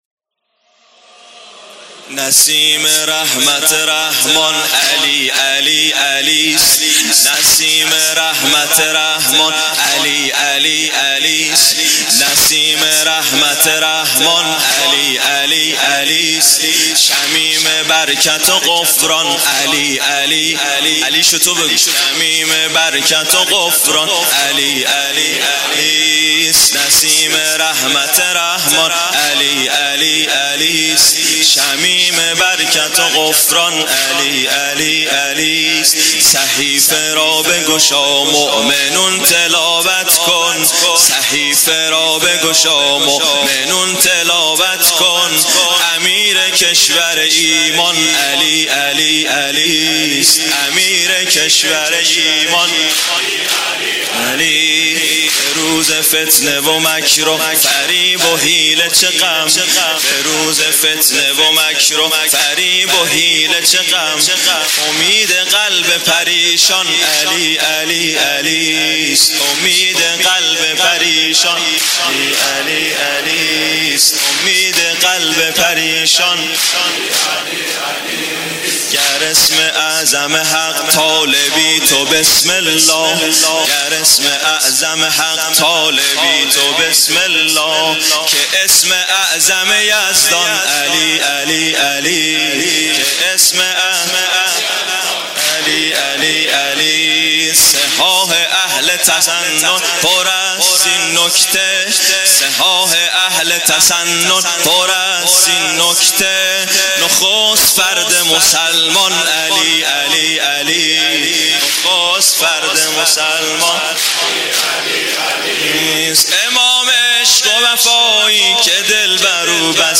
واحدتند
شب قدر